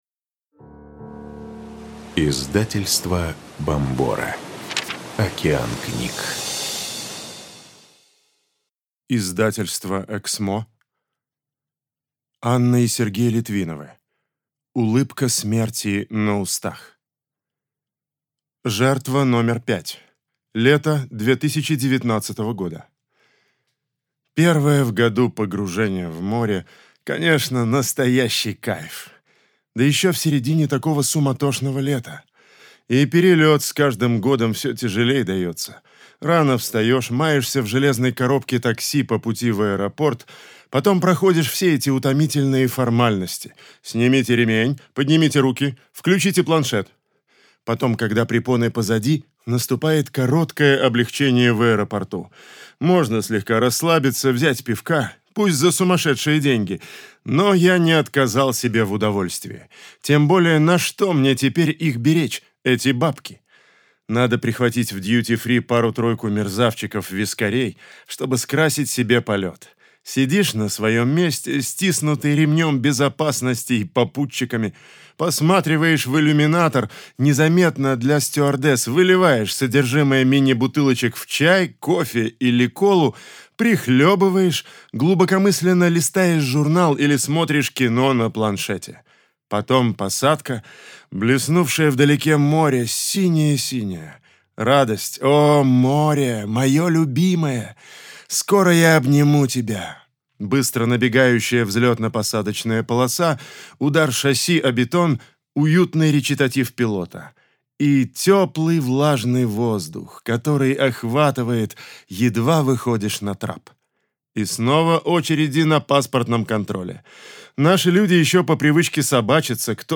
Аудиокнига Улыбка смерти на устах | Библиотека аудиокниг